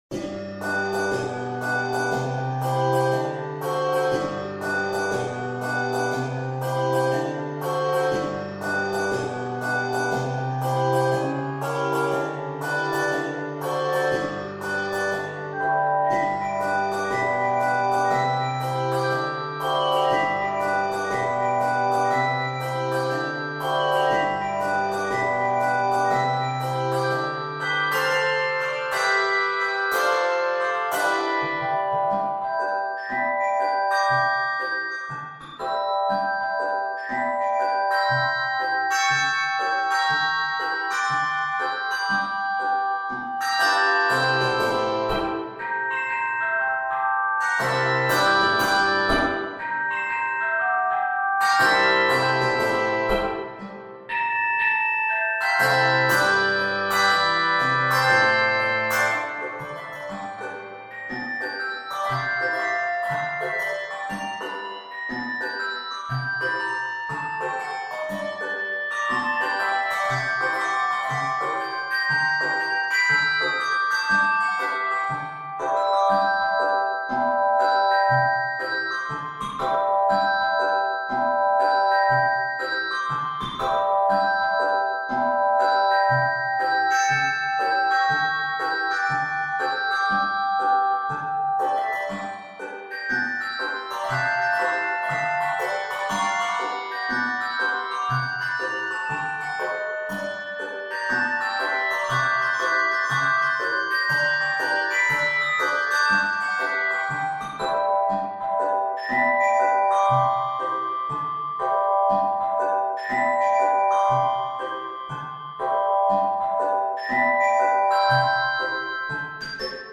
Key of G Major.